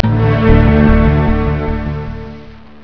Start-Up Sounds:
Start-Up_PowerMac_Card ..............Power Macintosh Q610 *